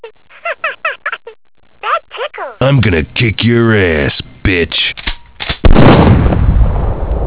Tickle Me Elmo getting shot by Duke Nukem.